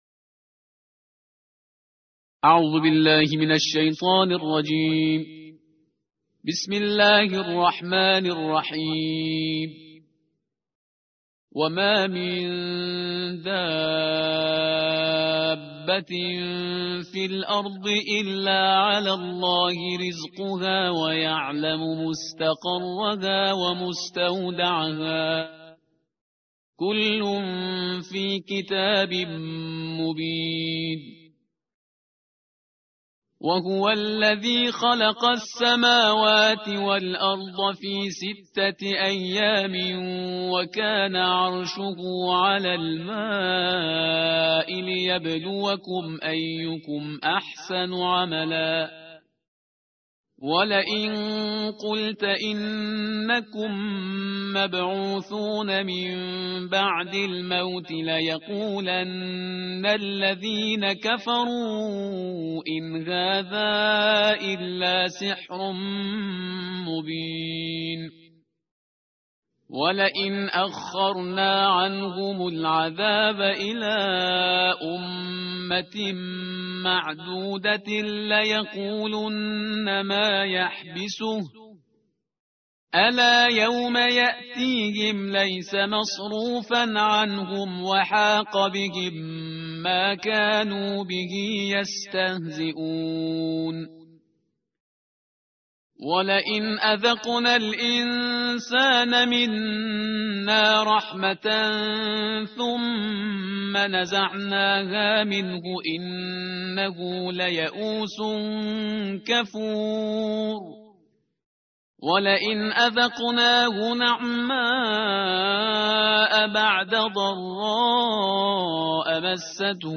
صوت/ ترتیل «جزء دوازدهم» قرآن کریم با صدای استاد شهریار پرهیزگار